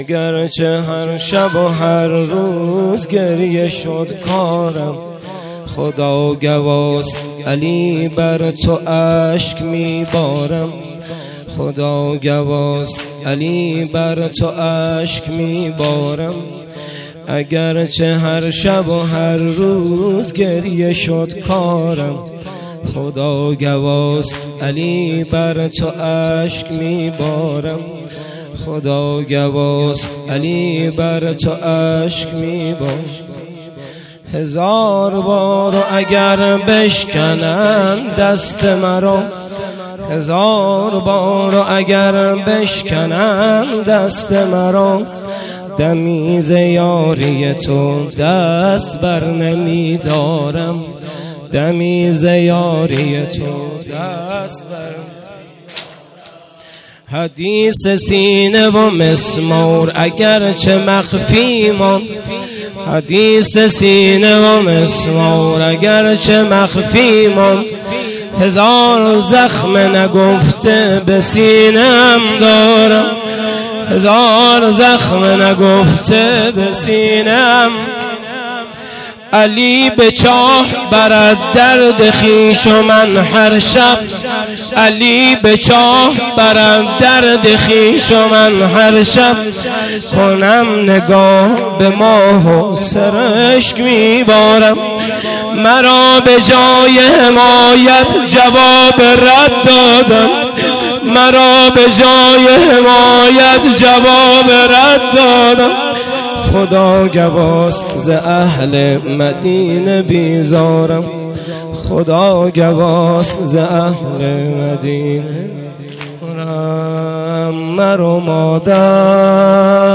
فاطمیه اول - سال 1397
مداحی ایام فاطمیه